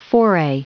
Prononciation du mot foray en anglais (fichier audio)
Prononciation du mot : foray